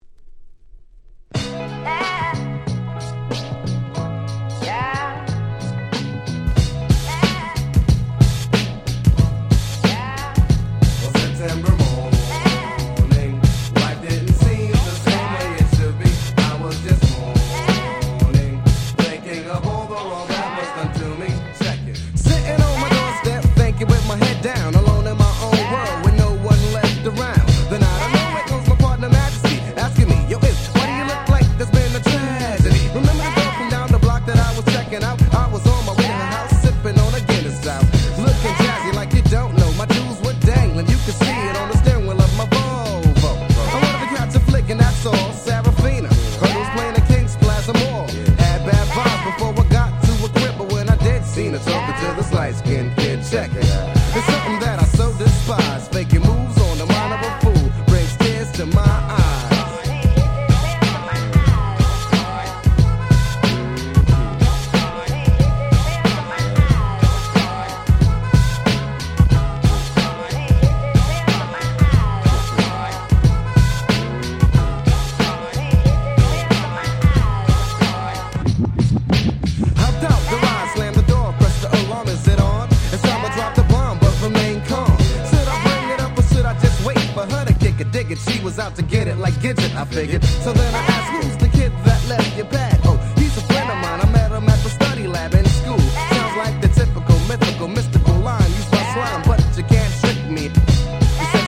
93' Super Nice Hip Hop / Boom Bap !!